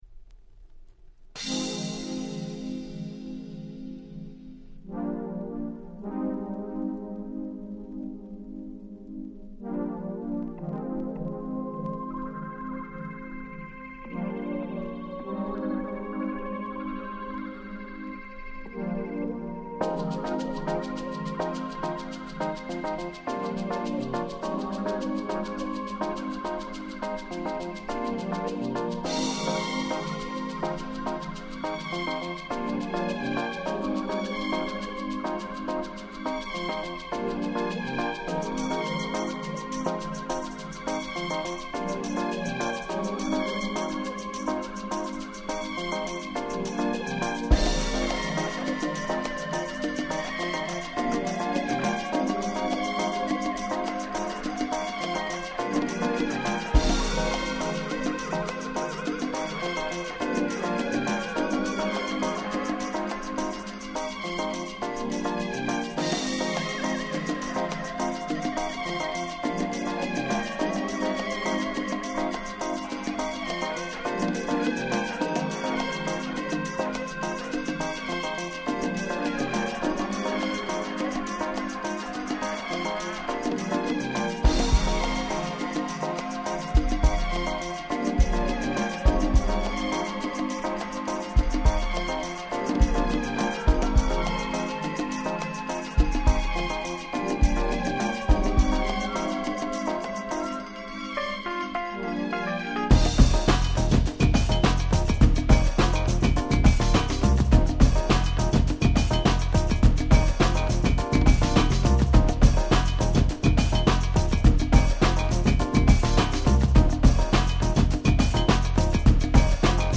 Rare Grooveファンからも凄く人気のある1枚です。